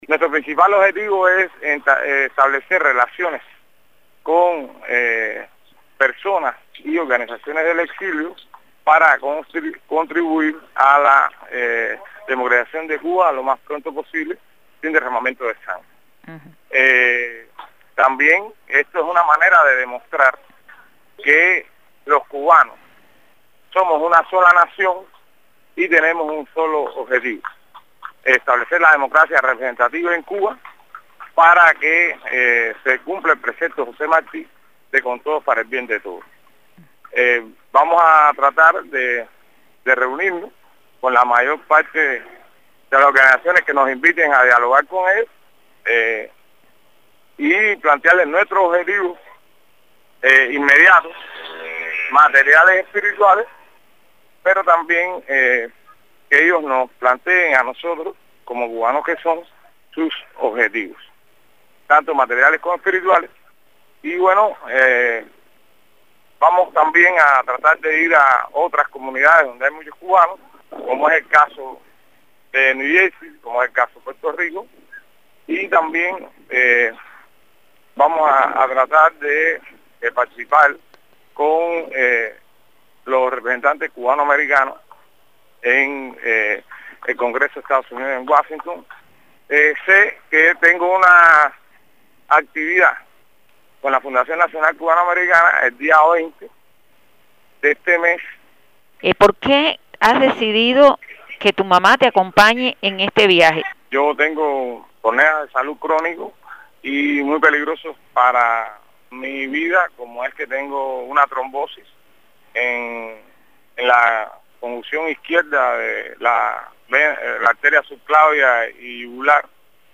Declaraciones de Guillermo Fariñas a martinoticias